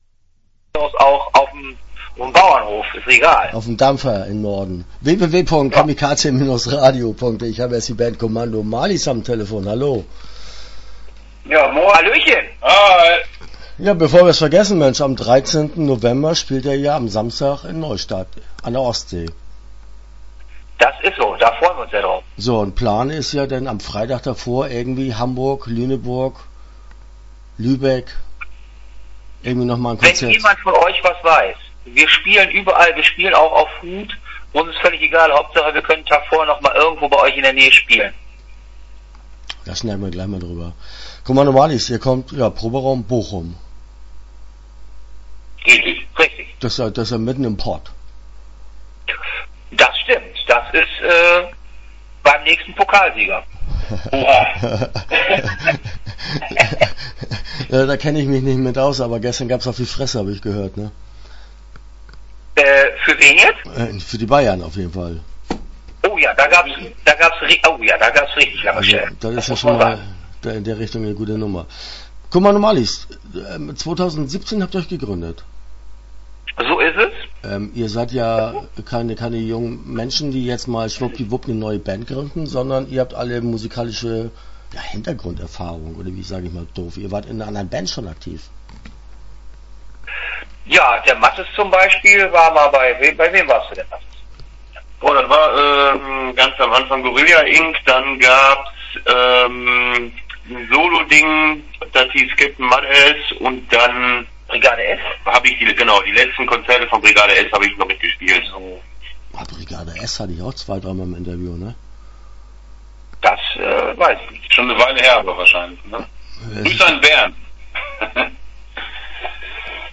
Start » Interviews » Kommando Marlies